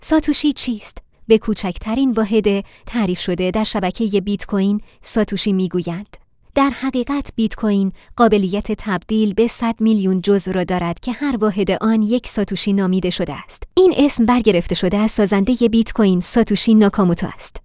کاربر گرامی این فایل صوتی، با کمک هوش مصنوعی فارسی، ساخته شده است.